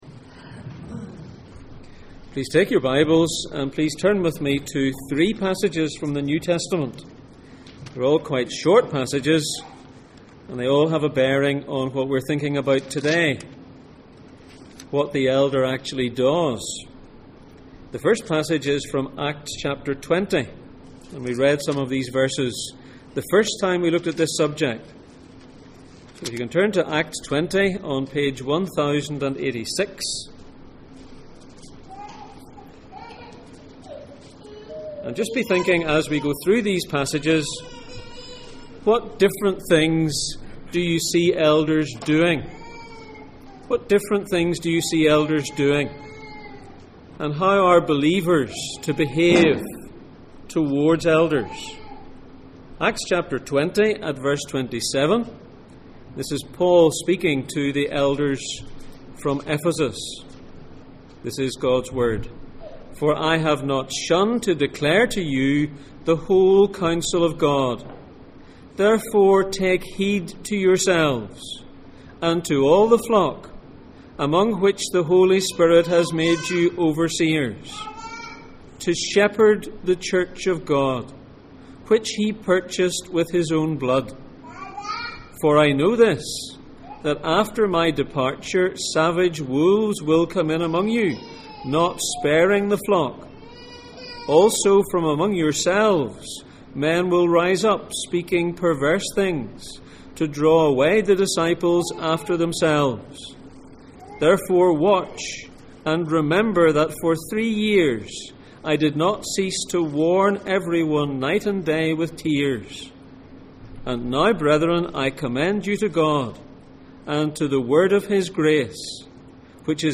1 Thessalonians 5:12-17 Service Type: Sunday Morning %todo_render% « What makes an Elder?